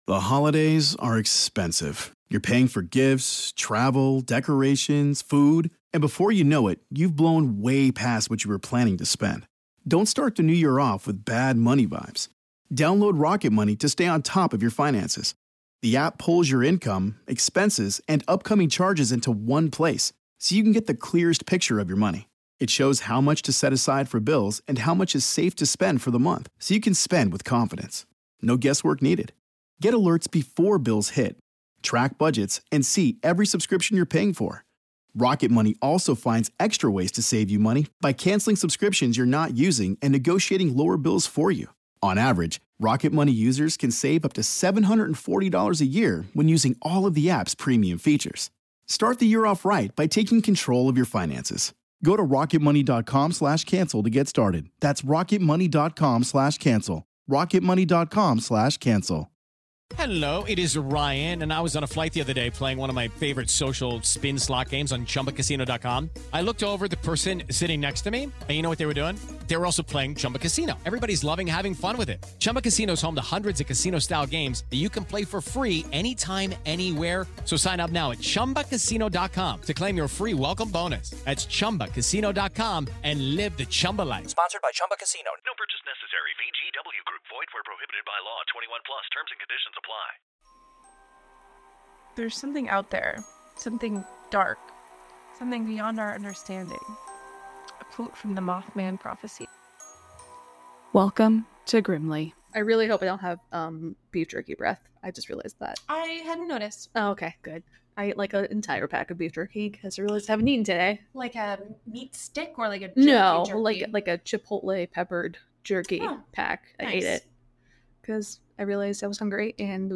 This week the girls retell the elusive Mothman story and its origins.